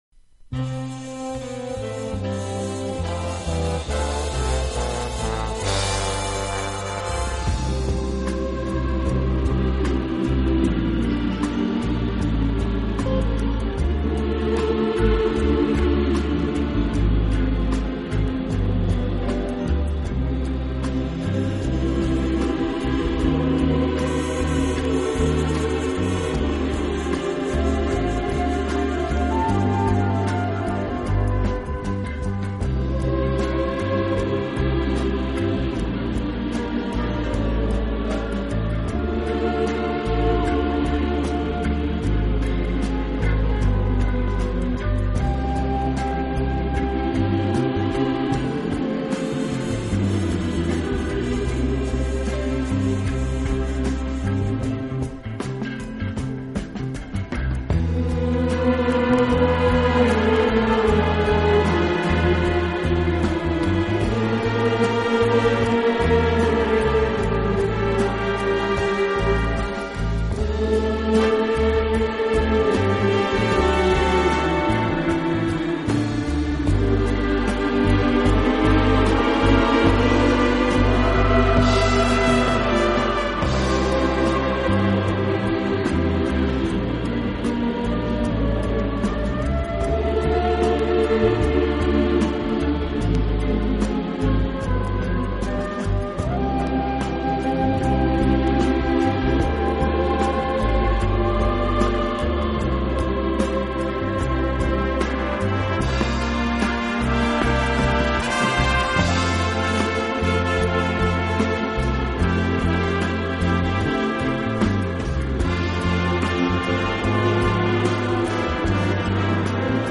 【顶级轻音乐】
此外，这个乐队还配置了一支训练有素，和声优美的伴唱合唱队。